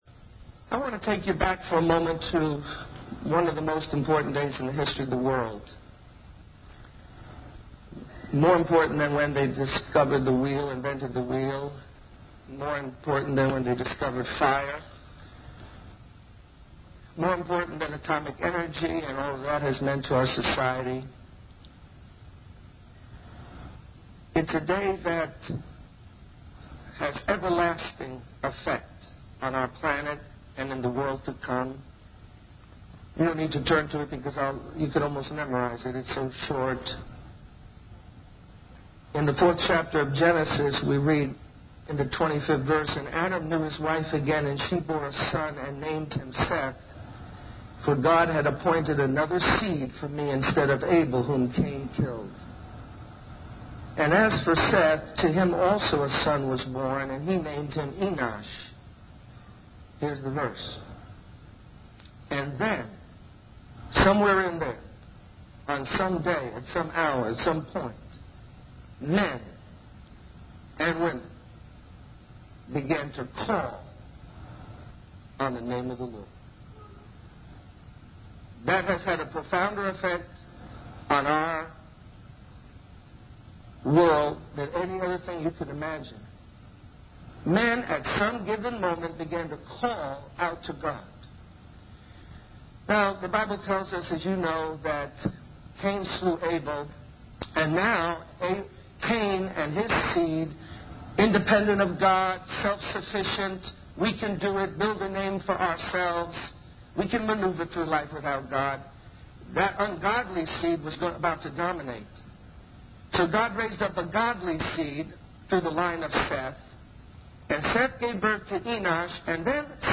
This sermon emphasizes the power and importance of calling on the name of the Lord in times of trouble and need. It recounts personal experiences and biblical principles that highlight the transformative impact of earnest prayer and calling out to God. The speaker shares how prayer and calling on God led to miraculous interventions and transformations in challenging situations, emphasizing the need for a deep, consistent, and faith-filled prayer life.